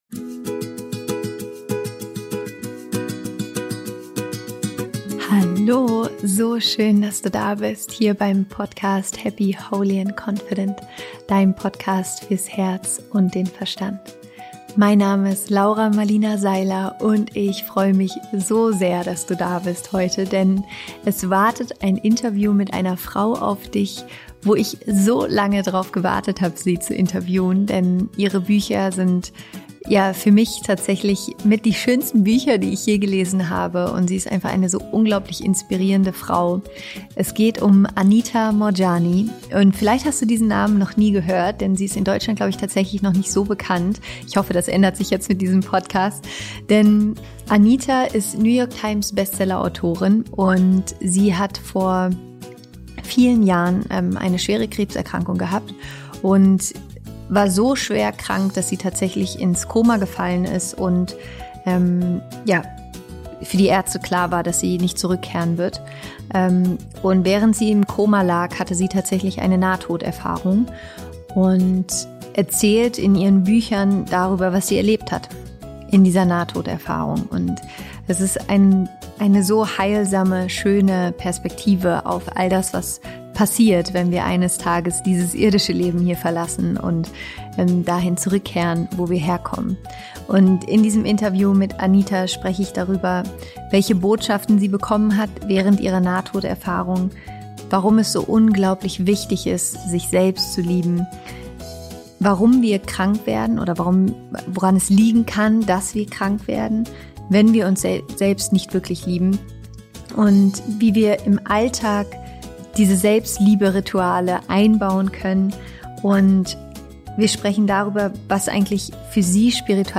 Liebe dich selbst, als hinge dein Leben davon ab - Interview Special mit Anita Moorjani